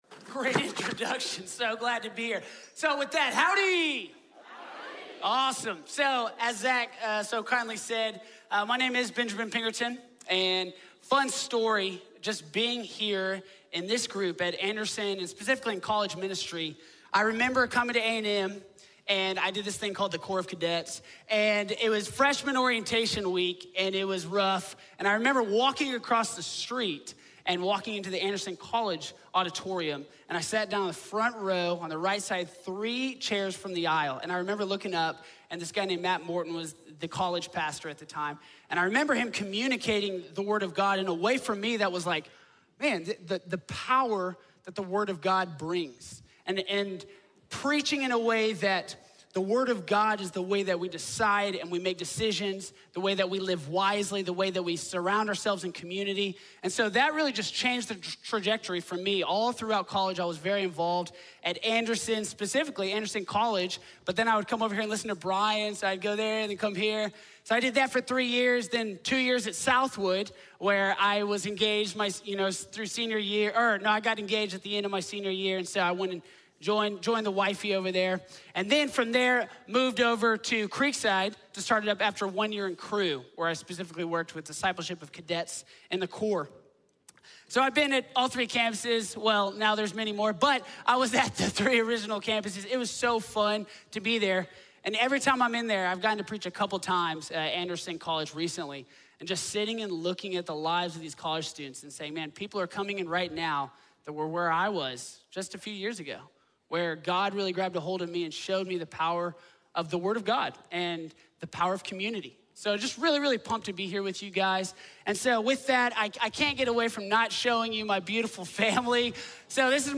King Josiah | Sermon | Grace Bible Church